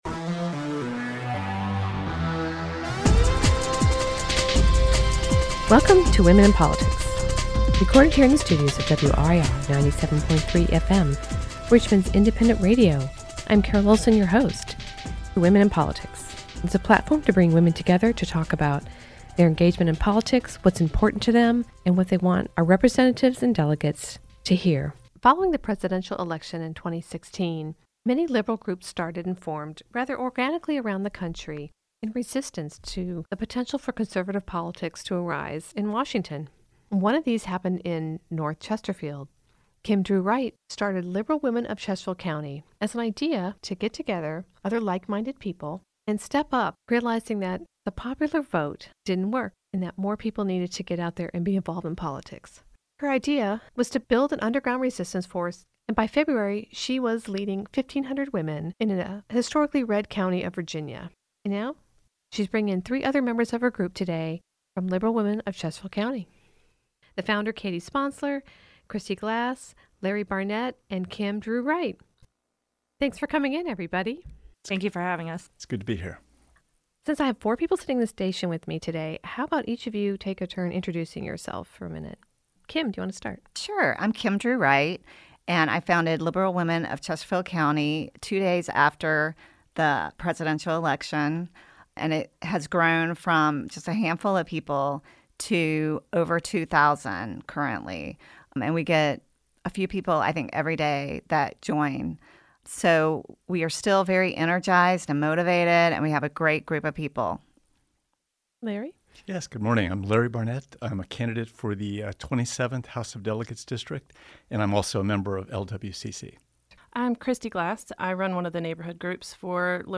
This show is in 2 parts, tune in June 8th at noon for the second installment.